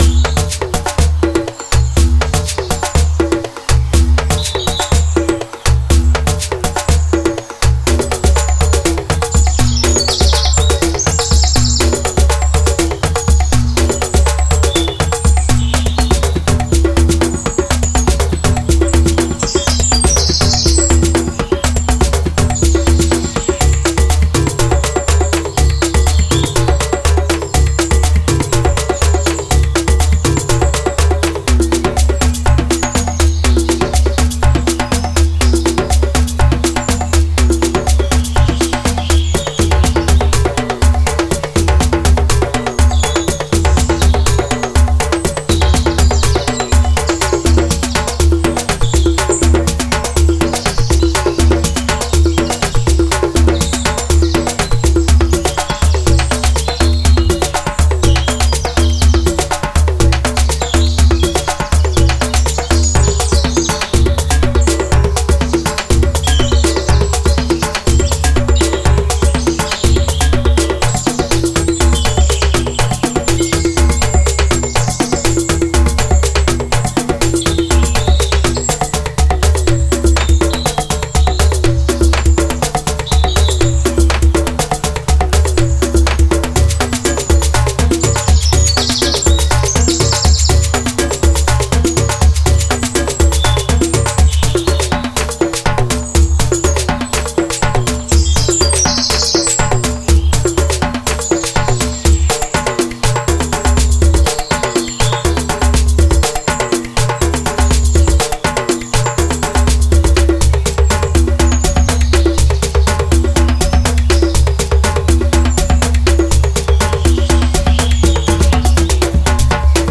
Genre:Africa
Percussions Africanは、あなたのトラックにオーガニックなエネルギーと部族的なスピリットを吹き込む、鮮やかでグルーヴ感に満ちたリズムをもたらす究極のパスポートです。
土のように響くジェンベの低音から、カウベルの金属的なチャイム、トーキングドラムの催眠的なパルス、シェイカーの繊細で複雑なシャッフルまで、それぞれのループは汎用性の高い122 BPMで収録されており、プロジェクトに合わせてスピードアップやスローダウンも自在です。
収録されているのは、ベルケテ、ボンゴ、シェケレ、エクピリ、フロントン、サバール、サカラ、ウドゥなど、伝統的なアフリカン・パーカッションを見事に演奏・収録した豊かな音色の数々。
デモサウンドはコチラ↓